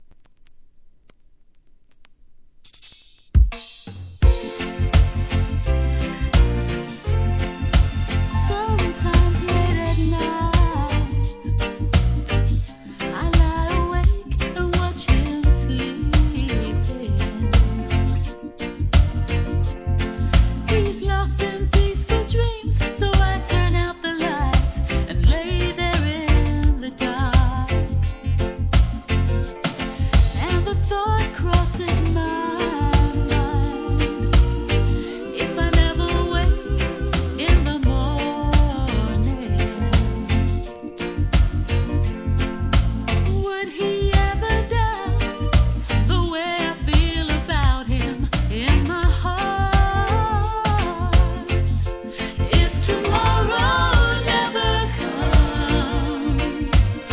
関連カテゴリ REGGAE